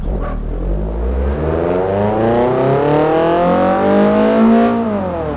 Index of /server/sound/vehicles/tdmcars/focussvt